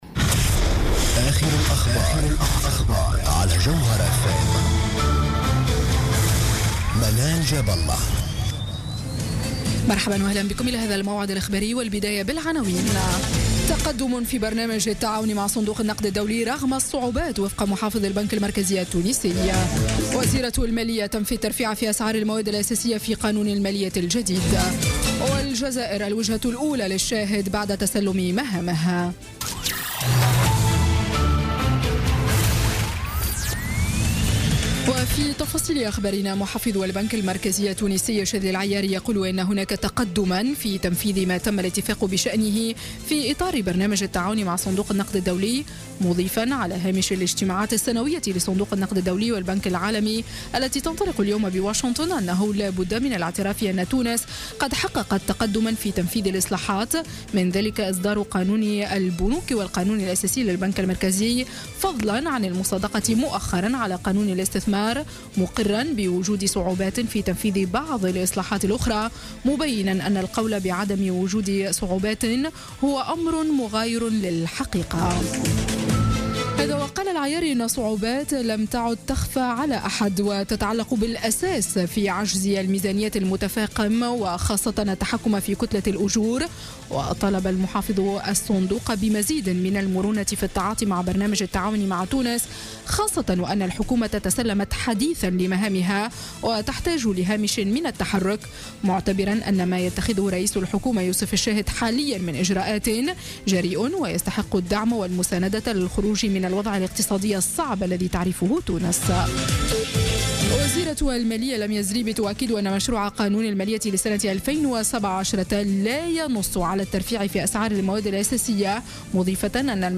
نشرة أخبار السابعة مساء ليوم الجمعة 7 أكتوبر 2016